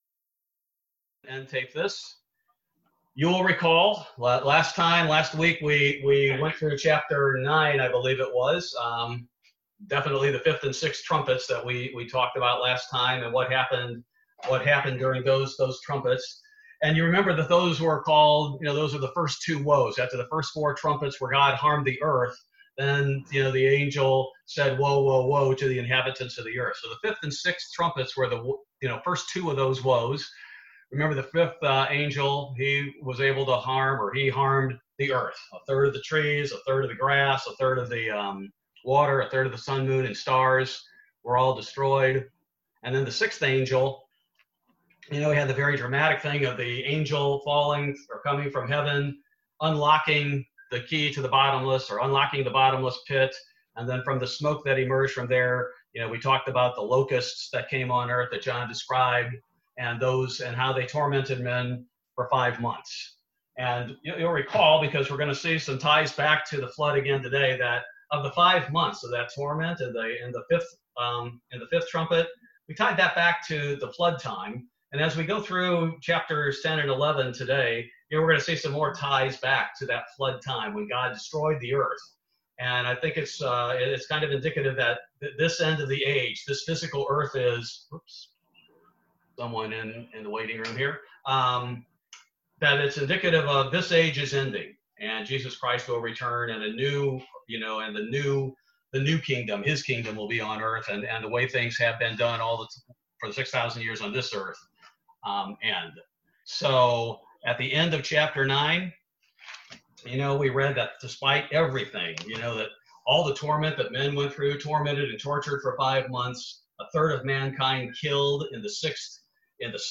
Weekly Bible Study. This Bible Study focuses primarily on Revelation 9 and 10